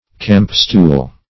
Search Result for " campstool" : Wordnet 3.0 NOUN (1) 1. a folding stool ; The Collaborative International Dictionary of English v.0.48: campstool \camp"stool`\ n. a folding stool.